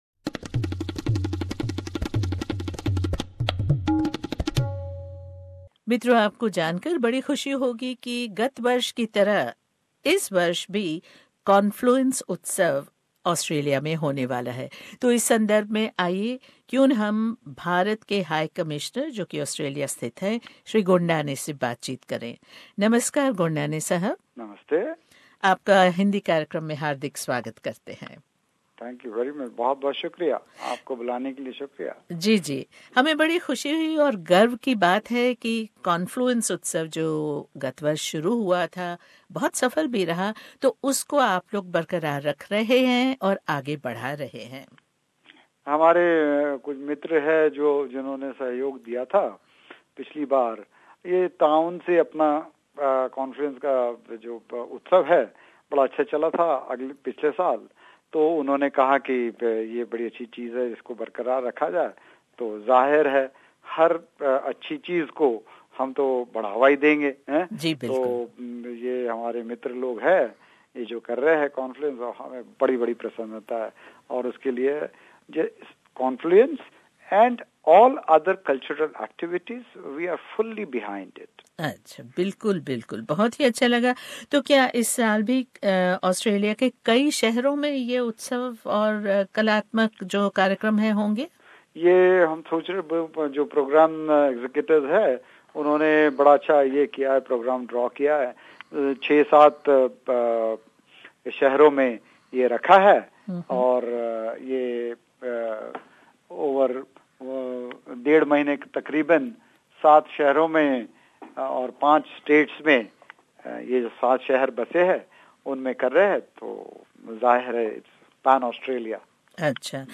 Confluence 2017 once again brings alive the essence of India in Australia with a diverse range of performances that showcase the classical and the contemporary form of dance, music, theatre, cinema, literature and puppetry. The Hindi Program spoke with His Excellency A. M. Gondane the Indian High Commissioner in Australia about this colourful festival.